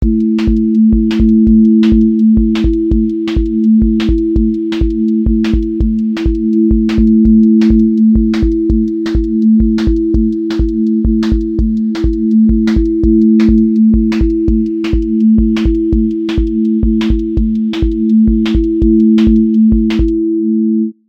QA Listening Test drum-and-bass Template: dnb_break_pressure
• voice_kick_808
• voice_snare_boom_bap
• voice_hat_rimshot
• voice_sub_pulse
• fx_space_haze_light
Dusty lofi x drum-and-bass break pressure with vinyl hiss, warm body, drifting motion, and switchups